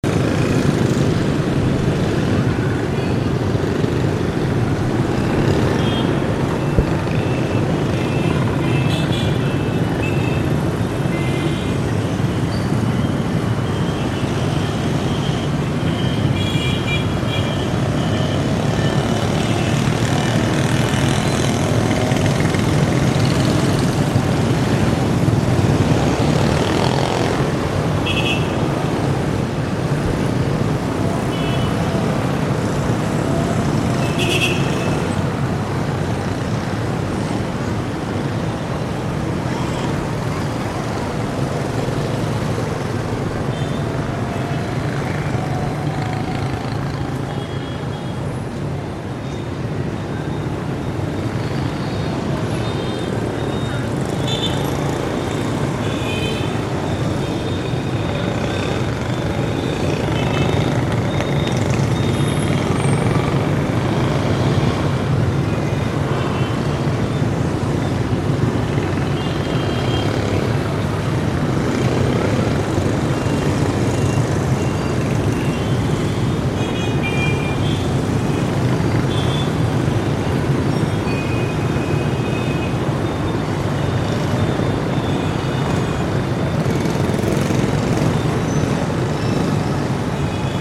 Etwa vier Millionen Mopeds sind täglich in Saigon unterwegs und sorgen dafür, daß Saigon zu fast keiner Tageszeit zur Ruhe kommt.
verkehr.mp3